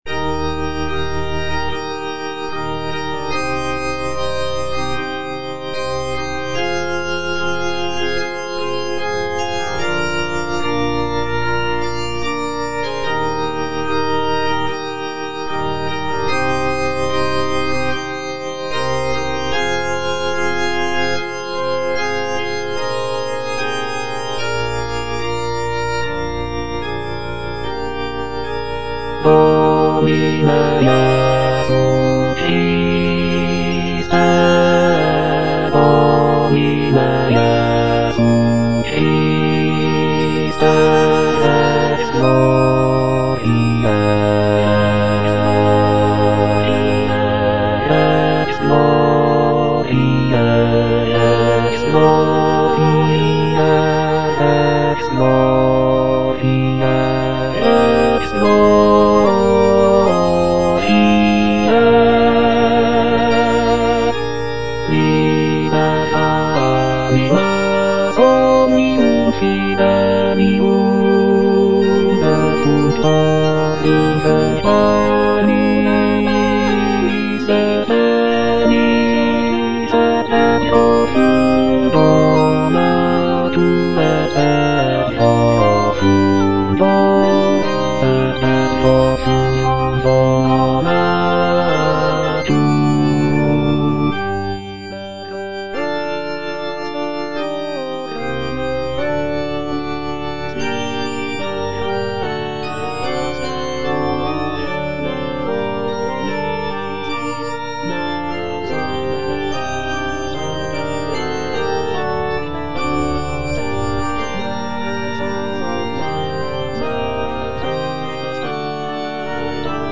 Bass (Emphasised voice and other voices) Ads stop
is a sacred choral work rooted in his Christian faith.